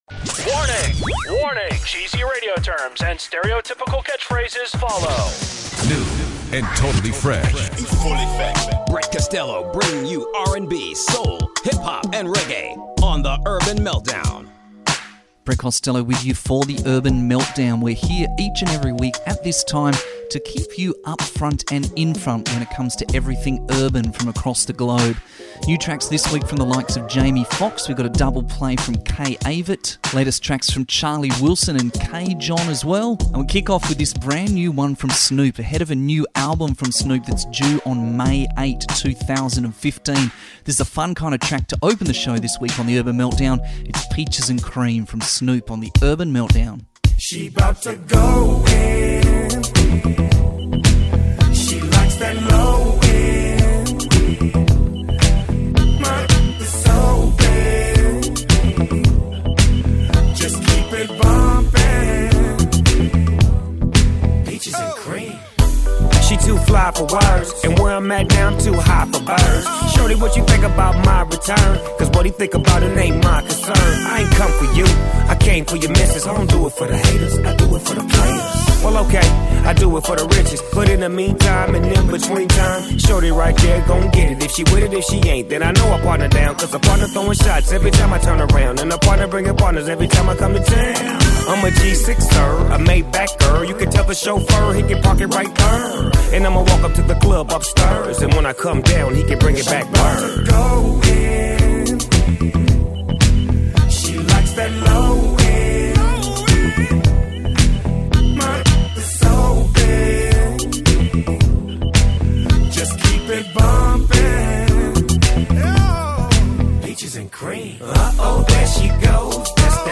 Keeping you upfront and in-front of the latest R&B jams.